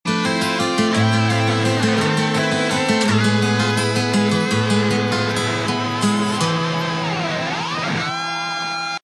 Category: Hard Rock / AOR / Prog